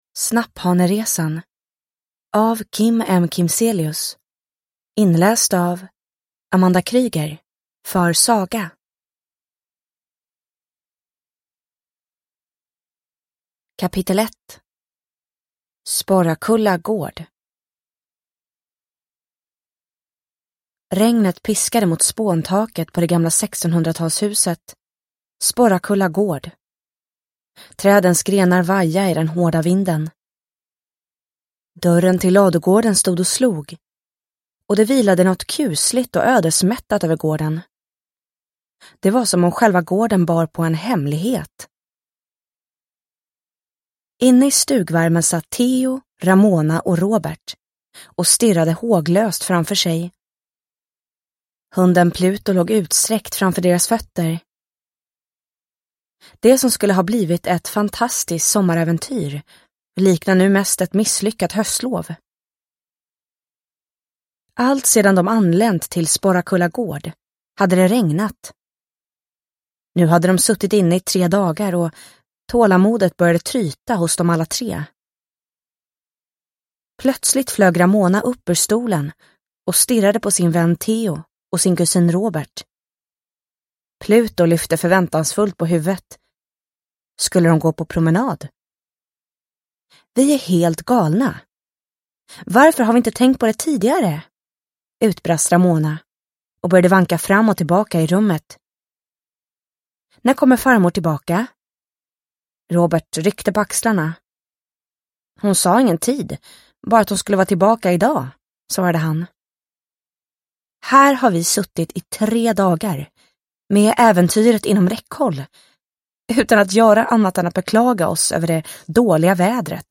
Snapphaneresan – Ljudbok – Laddas ner